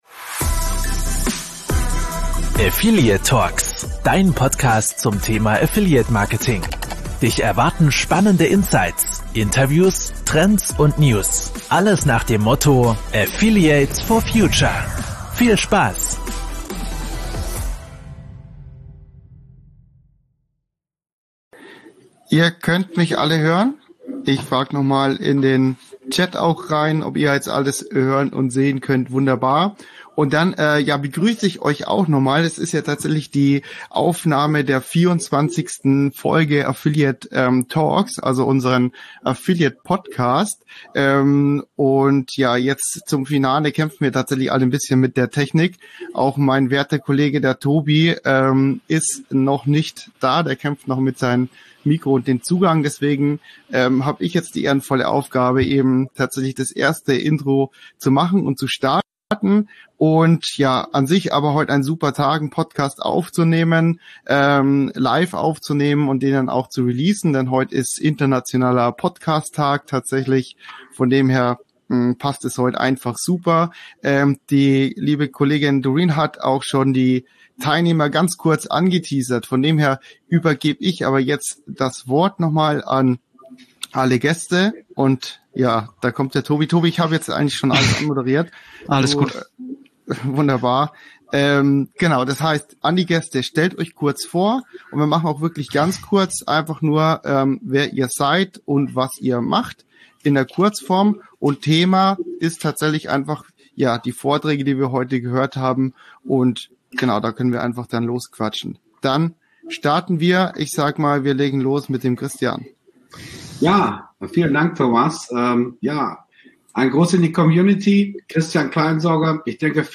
Spaß beiseite, in der heutigen Folge haben wir pünktlichst zum Tag des Podcastes wieder ein Live-Panel aufgenommen. Wir haben super tolle Gäste dabei und besprechen dort die aktuellsten Themen wie Ttdsg oder Tracking bei Social-Media Plattformen.